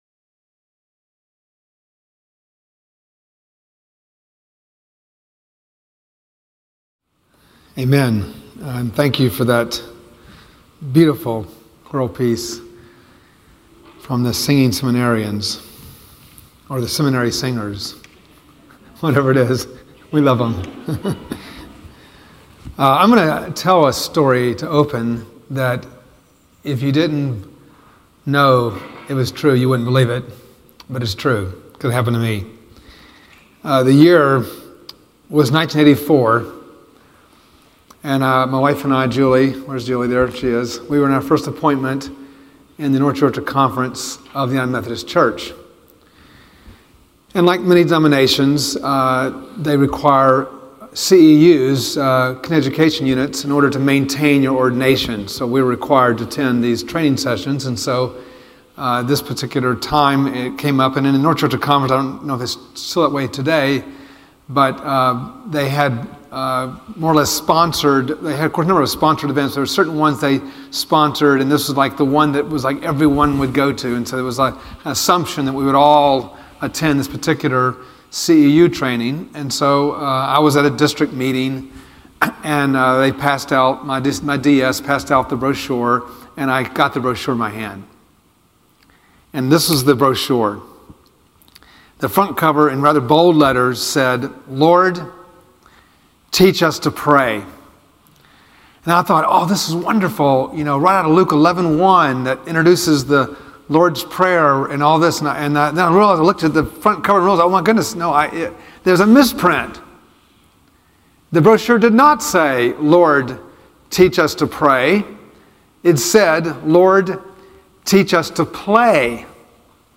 Faculty chapel services, 2019